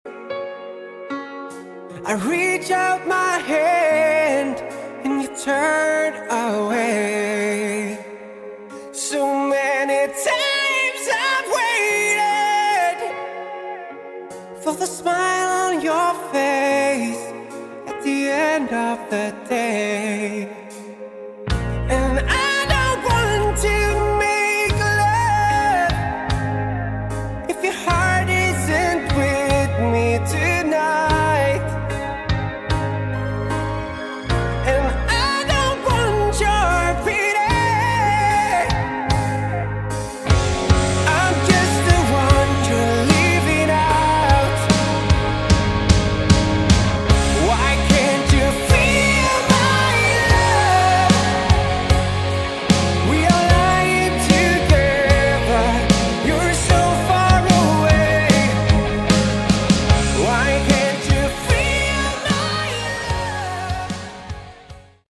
Category: Hard Rock
bass
lead vocals
rhythm guitars
keyboards
lead guitars
drums, additional guitar and keyboards
backing vocals